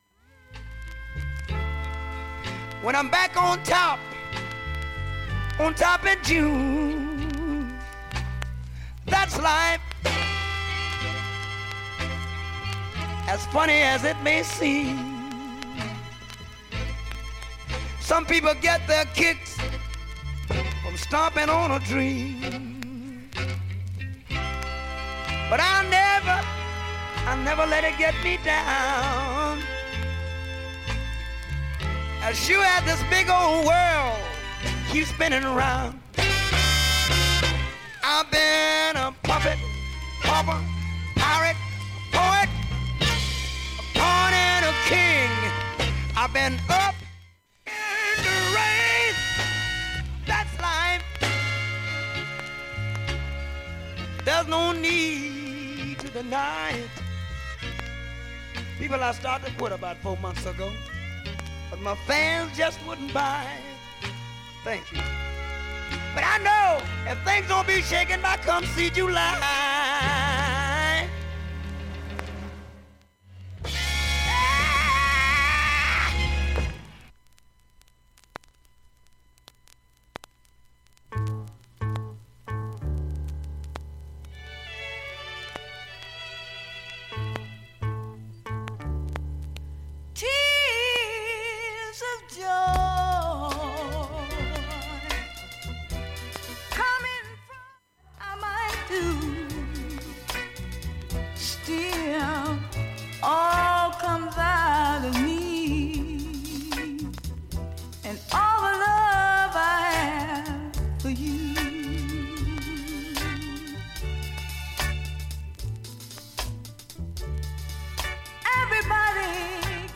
音圧、音質ともに良好です。
A-2に単発プツ3箇所、A-4に3回、A-6に2回と1回、
B-1に単発プツが６か所程度。
音圧が完全に勝り演奏中は全く影響無いレベルです。
◆ＵＳＡ盤オリジナル